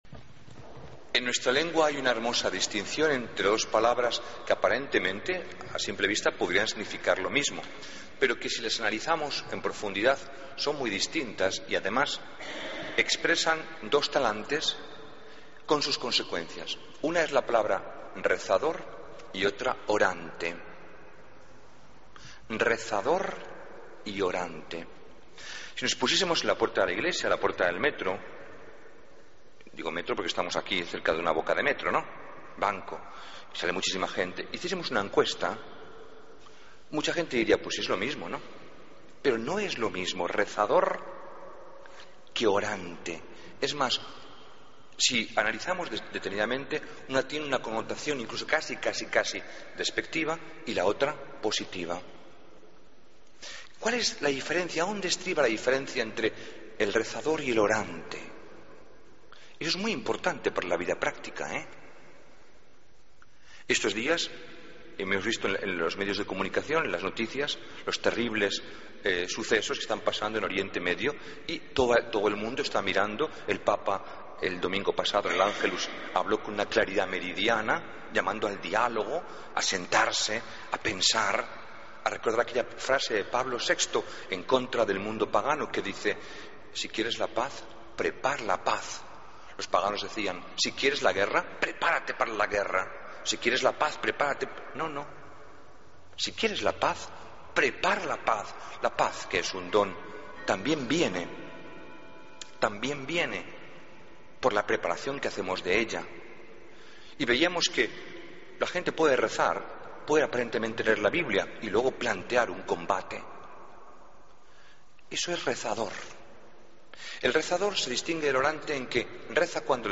Homilía del 1 de septiembre de 2013